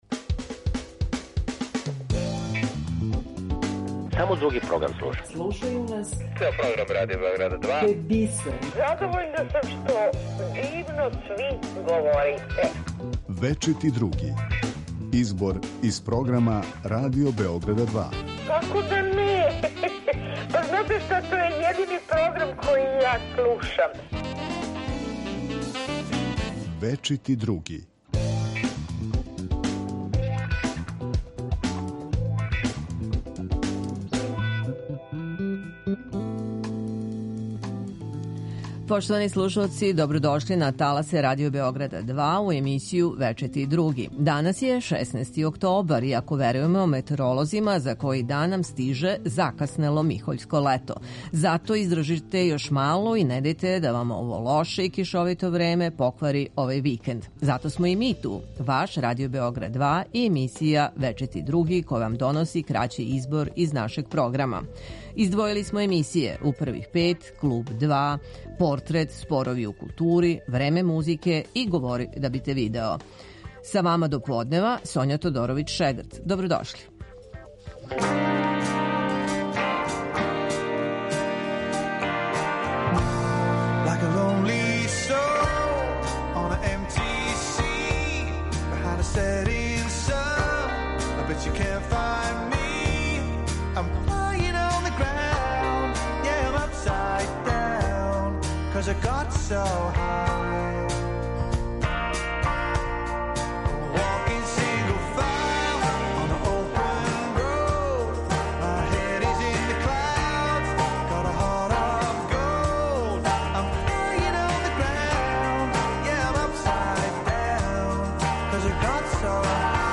У емисији Вечити Други чућете избор из програма Радио Београда 2.
Издвојили смо за вас одломке из емисијa У првих пет , Клуб 2 и Спорови у култури .